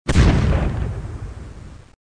Tank_Explosion.mp3